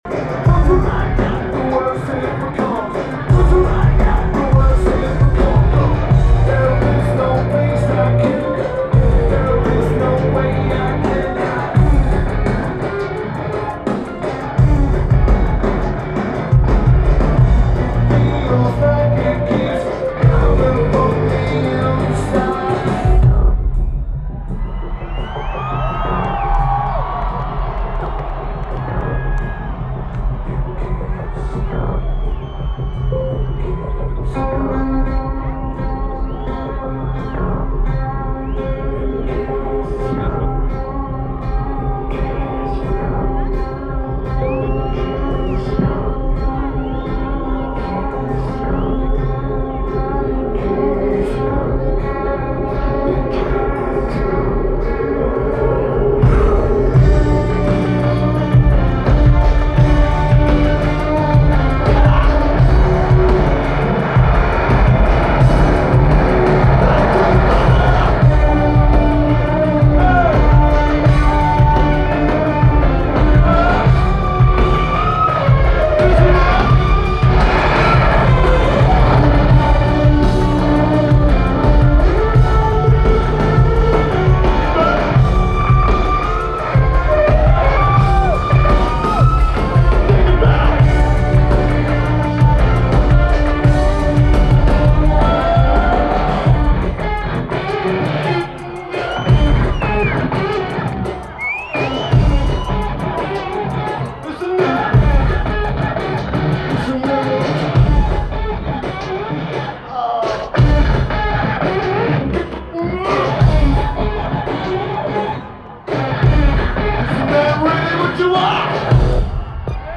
Red Rocks Amphitheatre
Lineage: Audio - AUD (CSB's + BB + JB3)